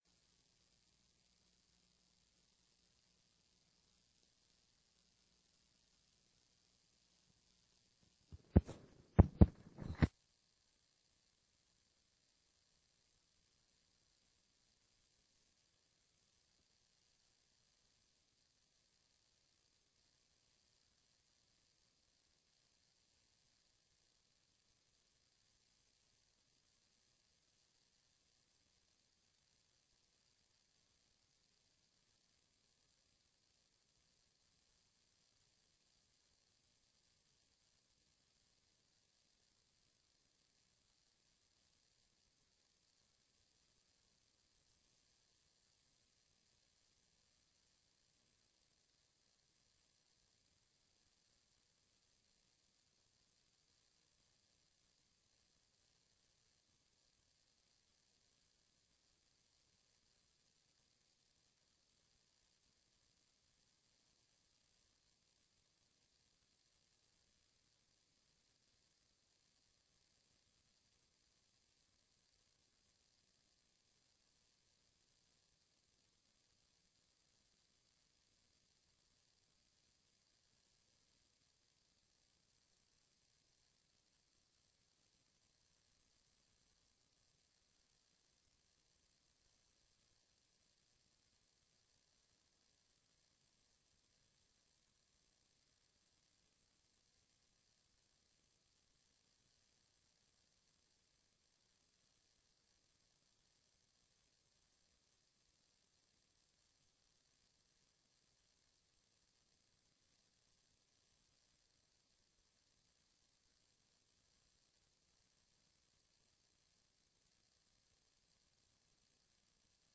TRE-ES - Sessão 16.12.15